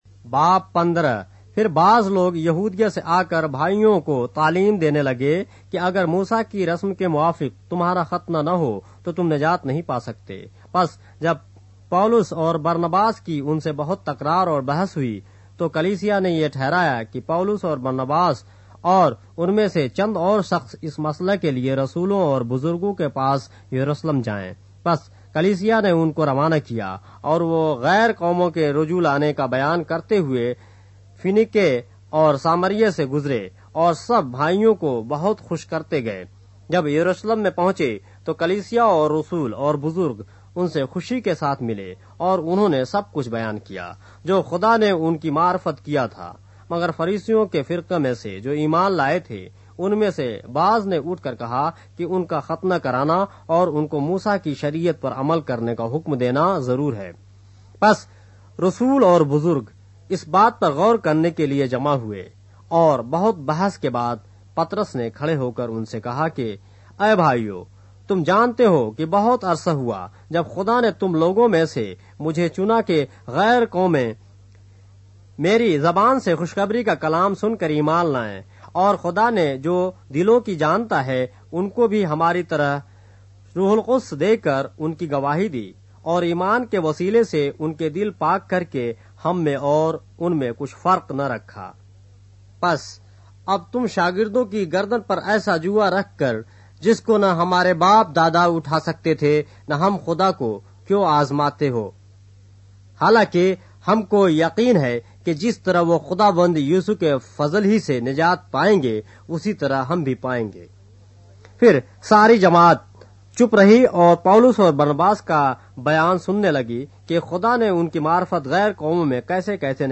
اردو بائبل کے باب - آڈیو روایت کے ساتھ - Acts, chapter 15 of the Holy Bible in Urdu